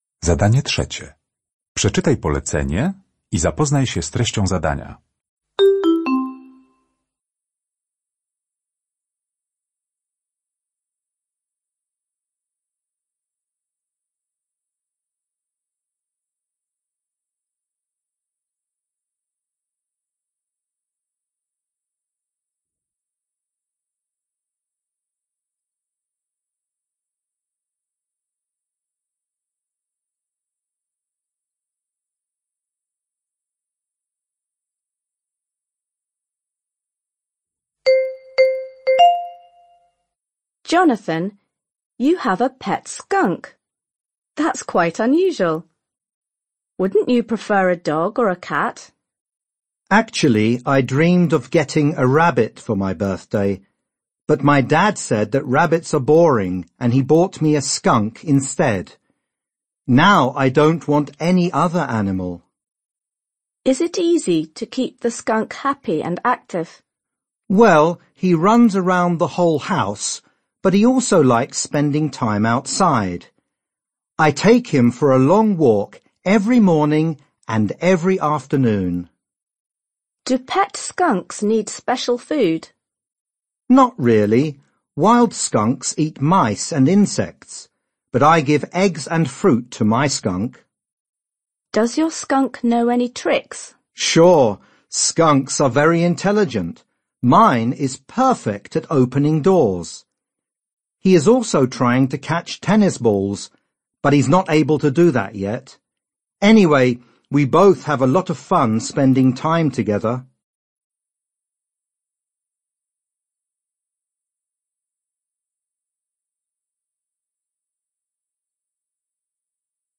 Uruchamiając odtwarzacz z oryginalnym nagraniem CKE usłyszysz dwukrotnie rozmowę na temat trzymania skunksa w domu.